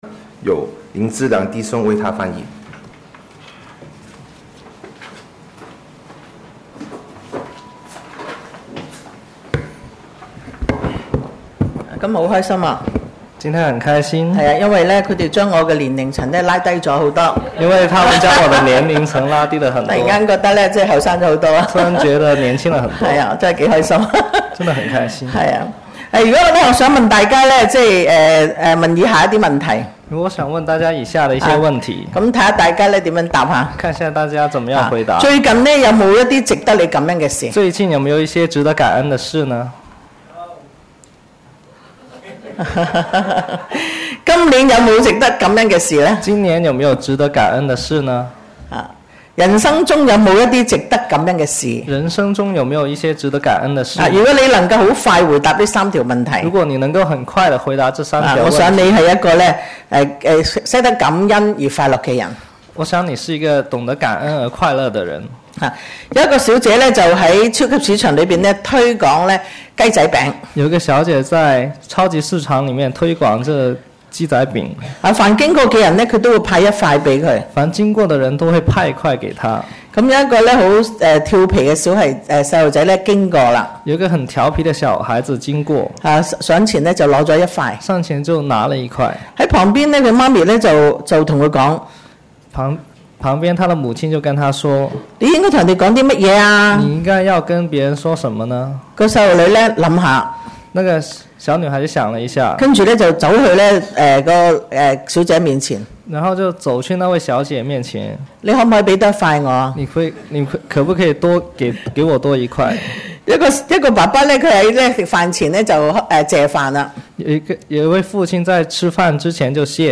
Posted in 主日崇拜 ← Newer 講道 Older 講道 →